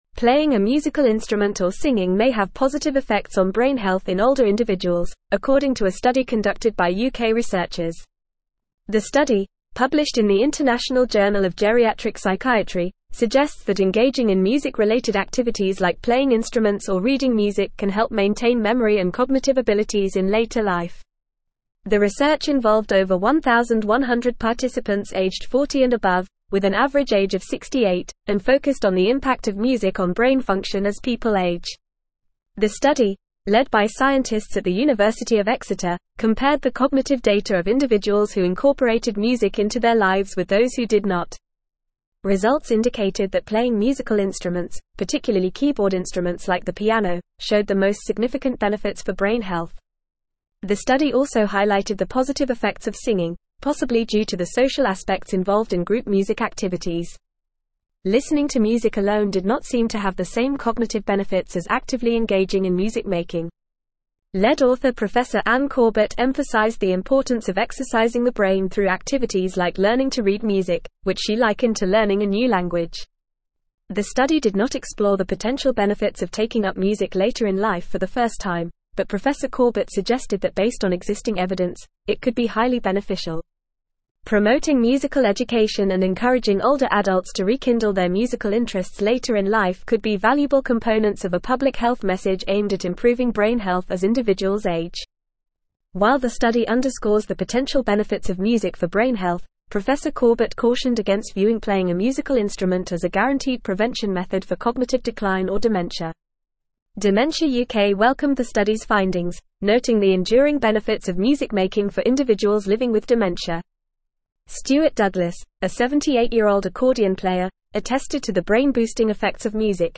Fast
English-Newsroom-Advanced-FAST-Reading-Music-and-Brain-Health-Benefits-of-Playing-Instruments.mp3